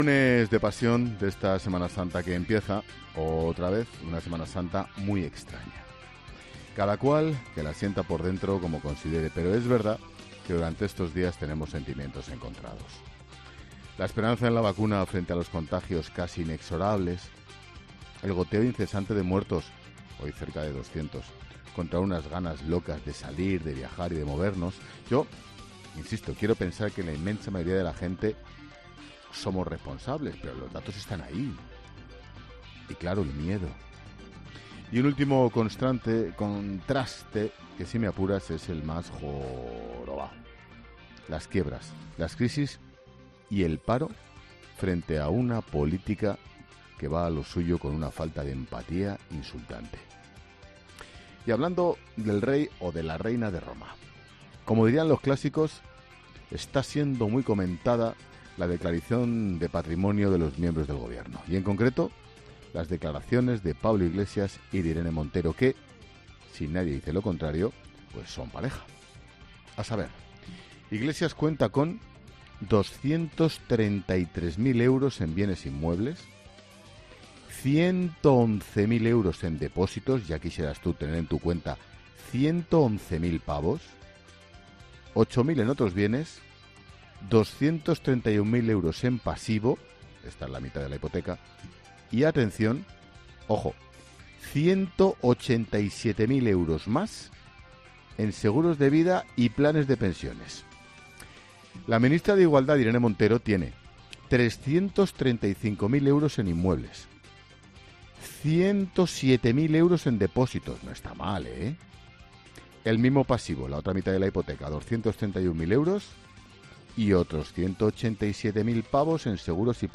Monólogo de Expósito
El director de 'La Linterna', Ángel Expósito, también desglosa en su monólogo la declaración de patrimonio de Pablo Iglesias e Irene Montero